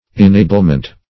Inablement \In*a"ble*ment\, n.